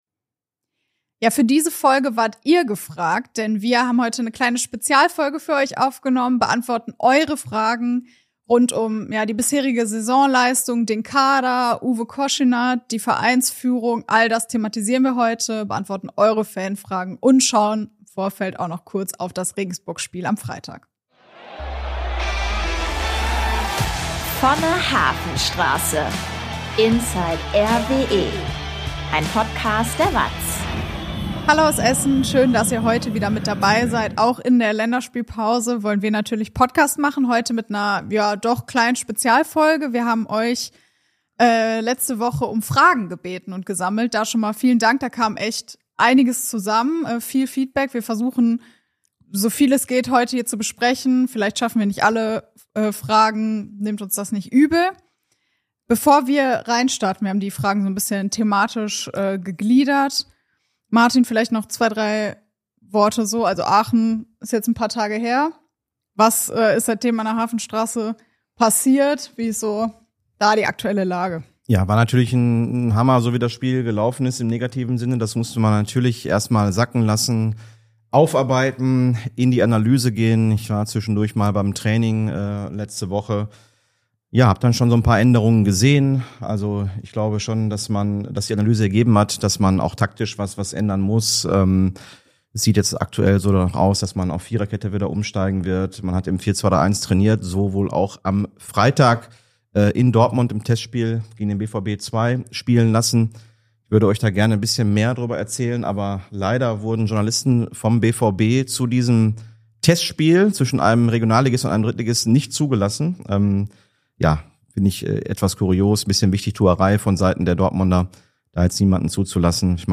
Stotterstart und Taktikfrage: RWE-Reporter beantworten eure Fragen ~ Vonne Hafenstraße - Inside RWE Podcast